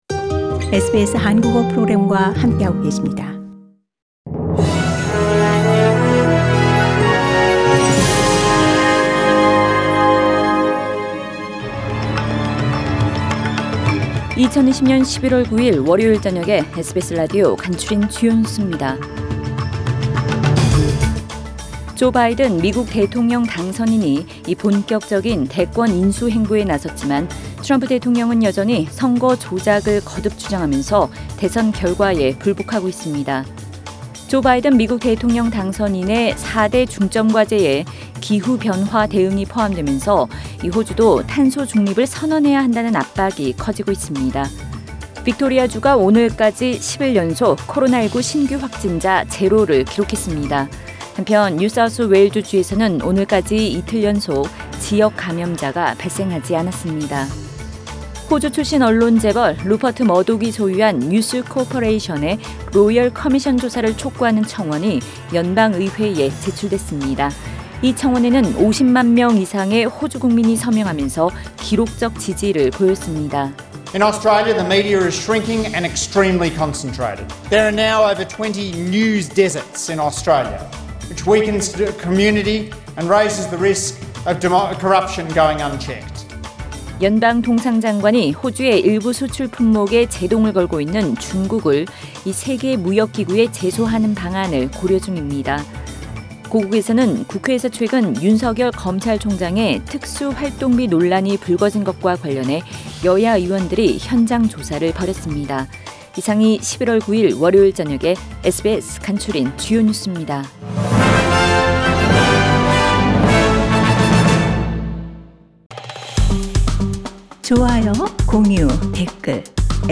SBS News Outlines…2020년 11월 9일 저녁 주요 뉴스